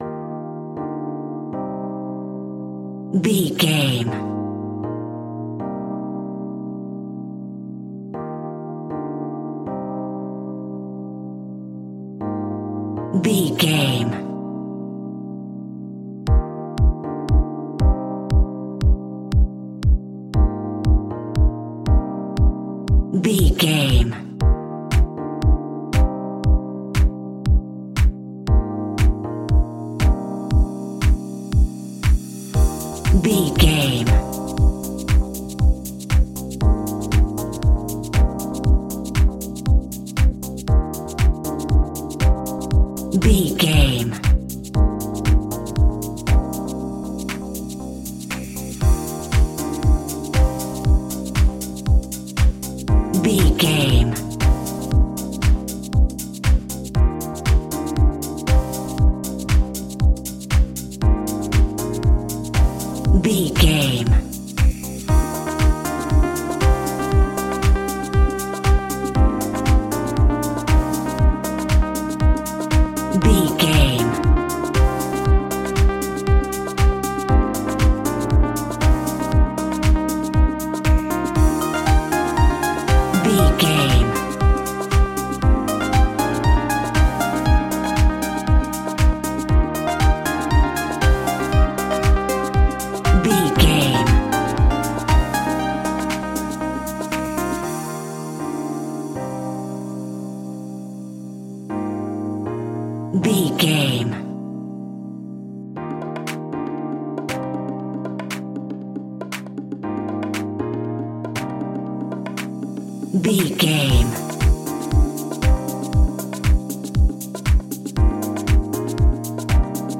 Ionian/Major
E♭
uplifting
energetic
bouncy
electric piano
drum machine
synthesiser
house
electro house
synth pop
synth leads
synth bass